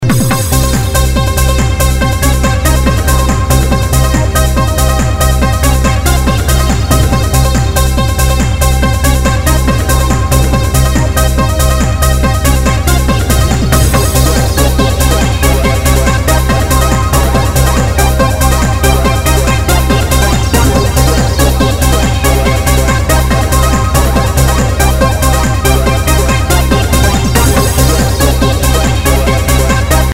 Стиль Psy-Trance